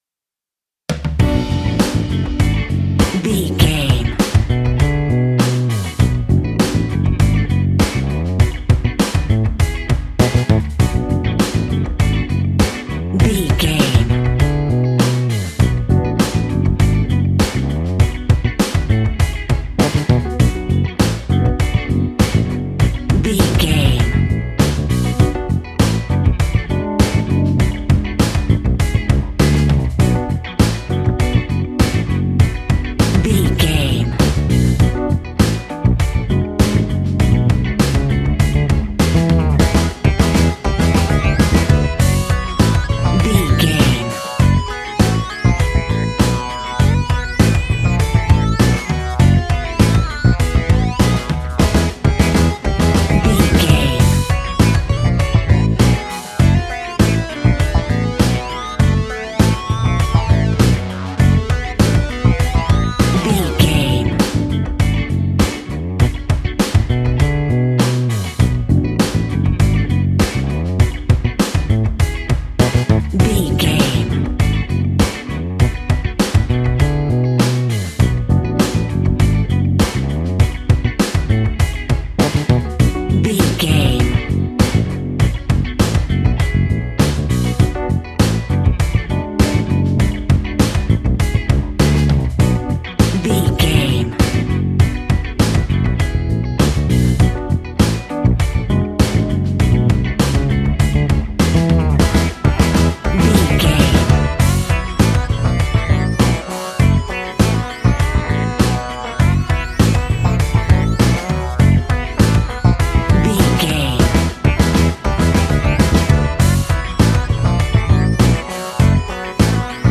Aeolian/Minor
G♭
groovy
funky
lively
electric guitar
electric organ
drums
bass guitar
saxophone
percussion